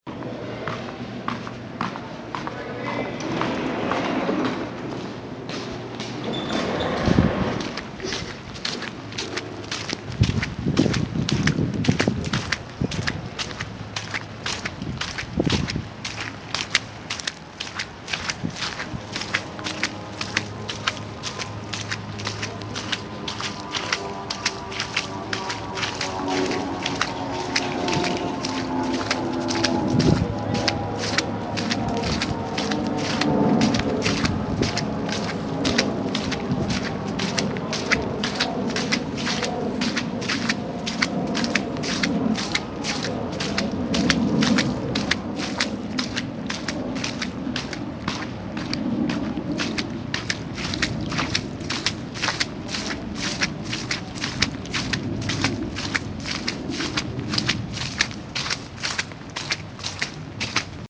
Field Recording 7
Student Center and outside it I walk out of the student center in my slightly-too-big snow boots. You can hear the doors slide open with a squeak and my footsteps change from a “clopping” noise to a wet “double-thump” as I walk through the slush. An airplane rumbles overhead and you can hear it fade into and out of hearing. At a certain point, the sidewalk dries up and makes way to an even more slush-y area and you can once again hear the change in my footsteps.
Walking1.mp3